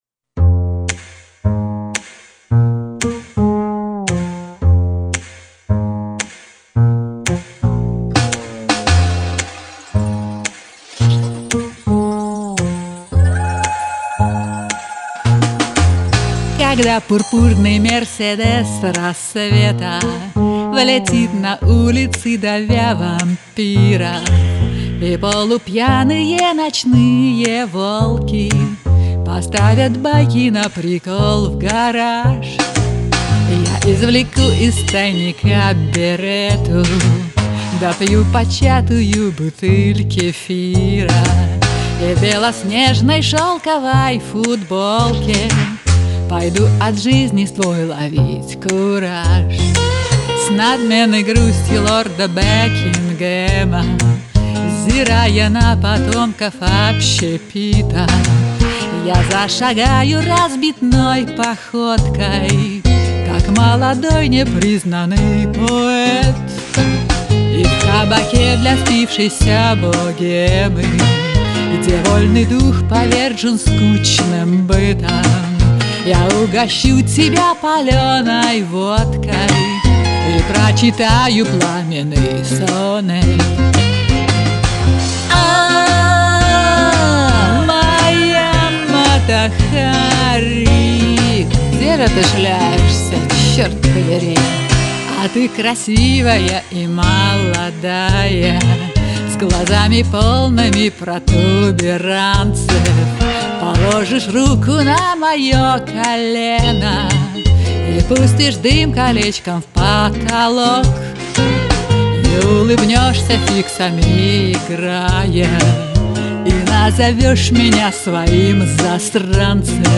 СТИЛЬНО И СМАЧНО СПЕТО!!!